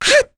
Rephy-Vox_Attack3.wav